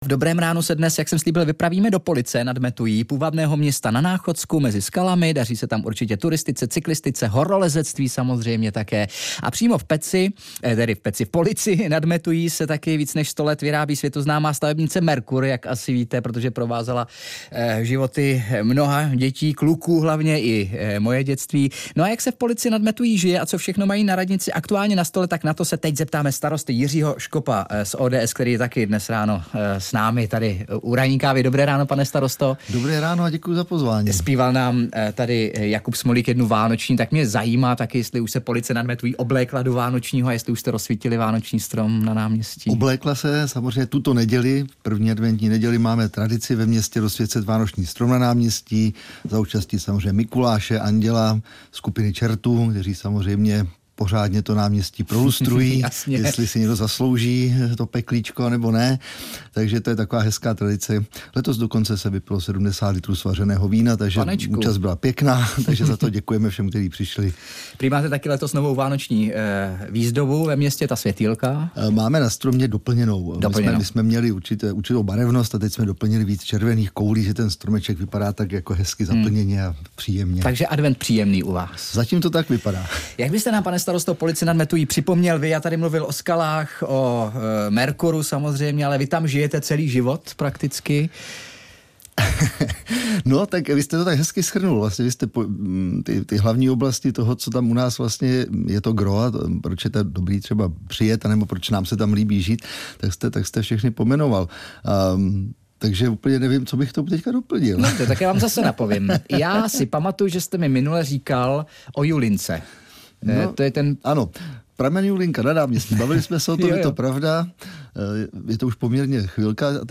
Host ve studiu: Starosta Police nad Metují: Spolky lidi stmelují, jsou základem života malého města či velké vesnice - 04.12.2024